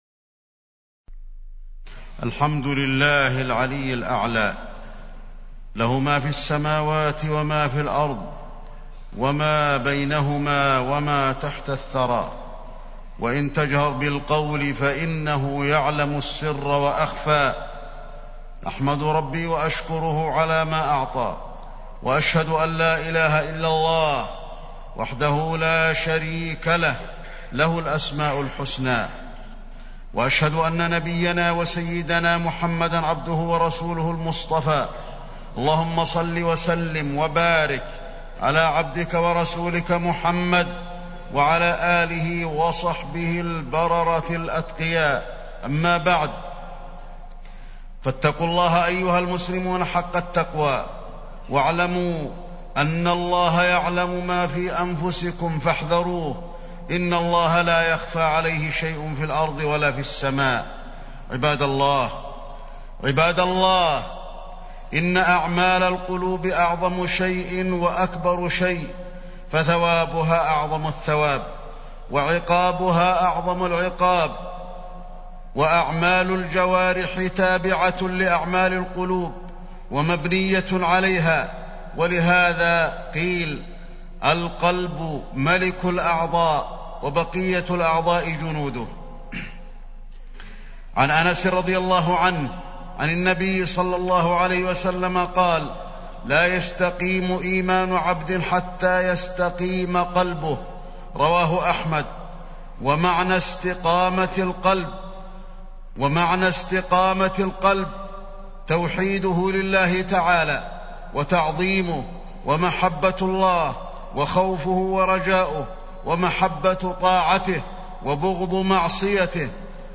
تاريخ النشر ٨ شعبان ١٤٢٧ هـ المكان: المسجد النبوي الشيخ: فضيلة الشيخ د. علي بن عبدالرحمن الحذيفي فضيلة الشيخ د. علي بن عبدالرحمن الحذيفي الخوف والرجاء